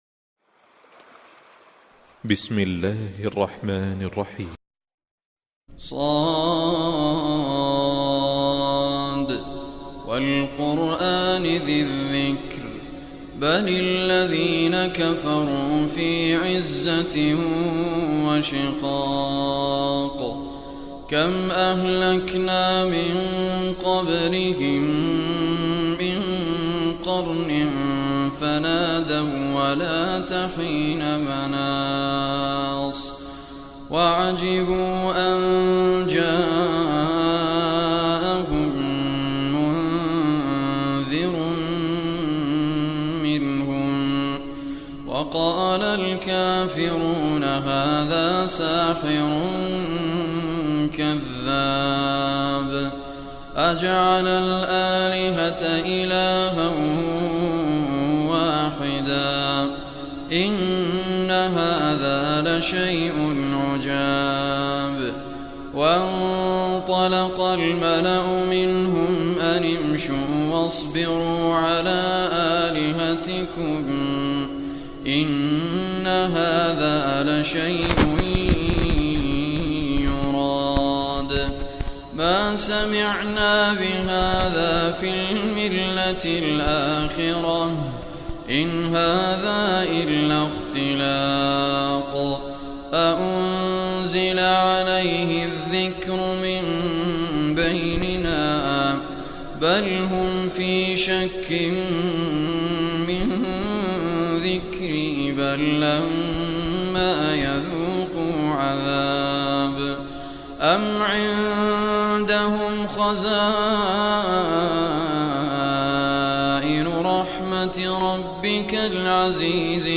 Surah Sad MP3 Recitation by Sheikh Raad Al Kurdi